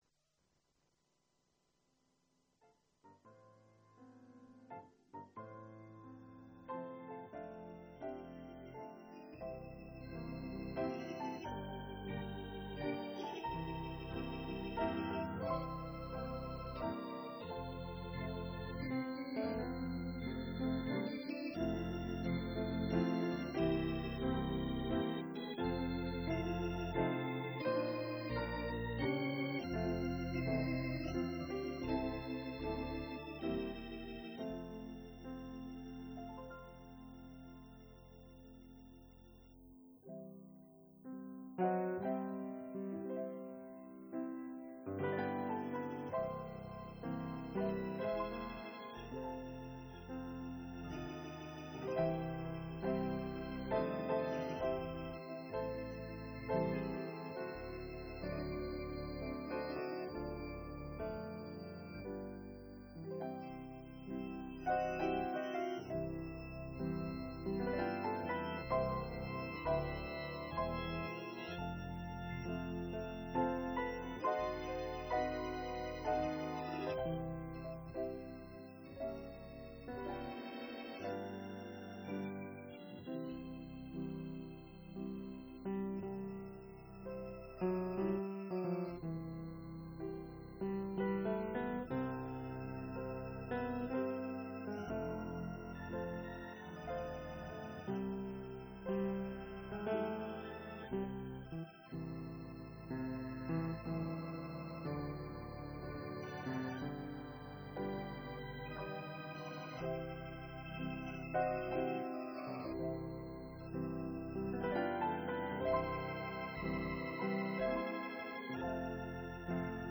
Gospel Tape Ministry NZ - Resource Room - Sunday Sermons